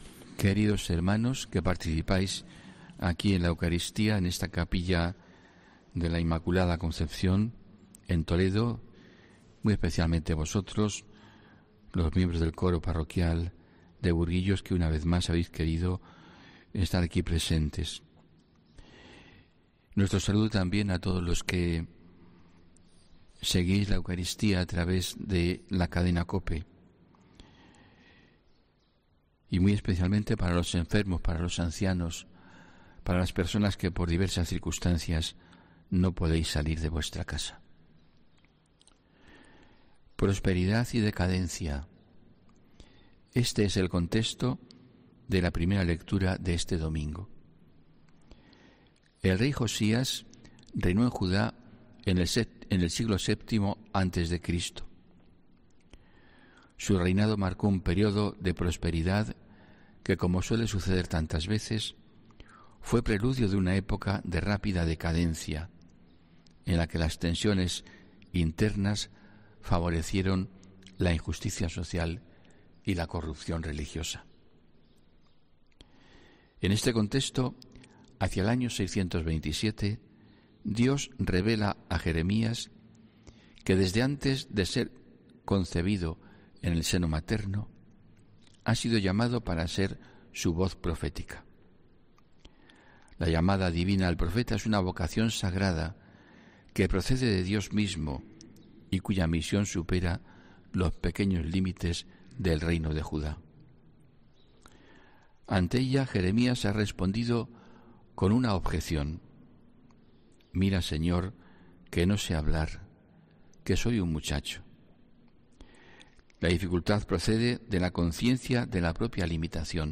HOMILÍA 30 ENERO 2022